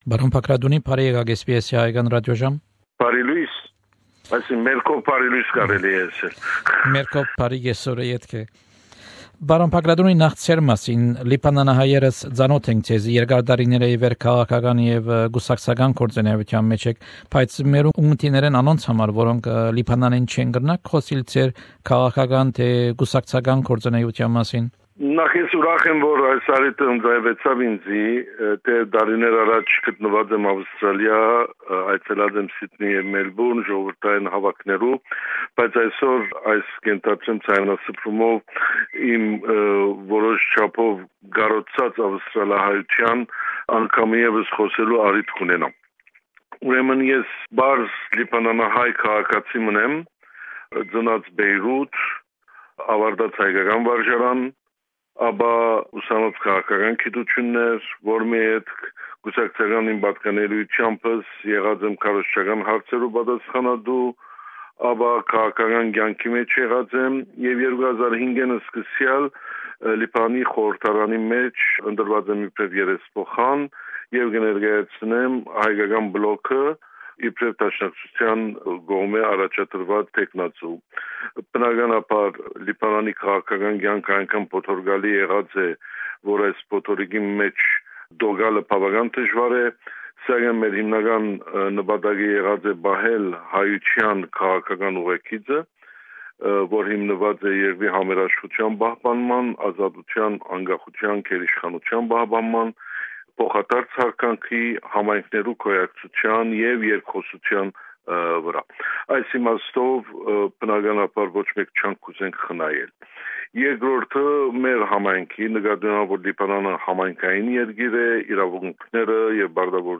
Հարցազրոյց ՀՅԴ Լիբանանի Կեդրոնական կոմիտէի եւ Հայկական Պատգամաւորական պլոգի ներկայացուցիչ, երեսփոխան Յակոբ Բագրատունիի հետ: Հարցազրոյցի գլխաւոր նիւթն է Լիբանանեան ընտրական օրէնքի փոփոխութիւնները, արտասահմանի մէջ ապրող լիբանանցիներու հնարաւորութիւնը մասնակցելու ընտրութիւններուն իրենց բնակած երկիրներուն մէջ եւ ինչու՞ անհրաժեշտ է որ արտասահման ապրող լիբանանահայեր քուէարկութեան մասնակցին: